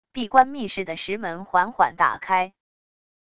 闭关密室的石门缓缓打开wav音频